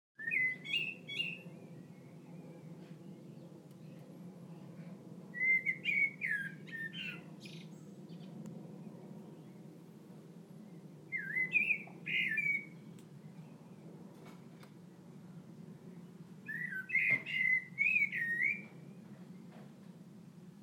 Blackbird
Enjoying sitting in the garden, listening to the evening birdsong.